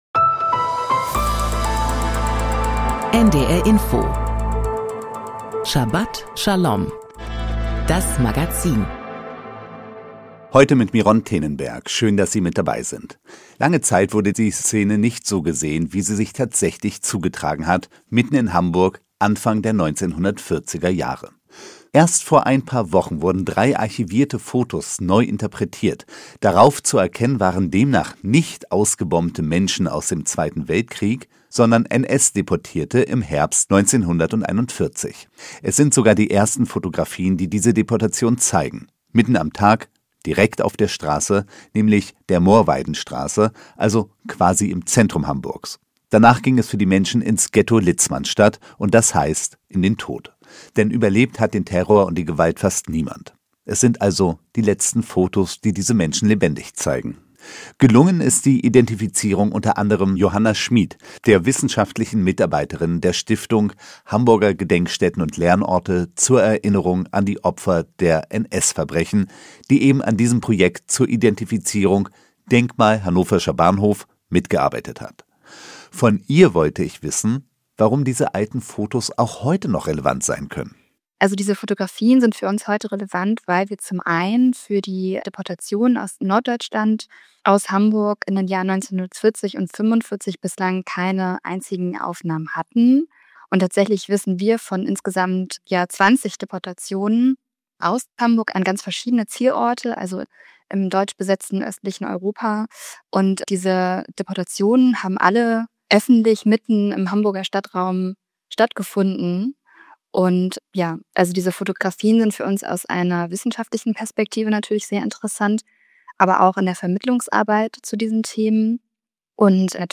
Interview
Thora-Auslegung